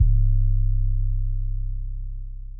808 luger.wav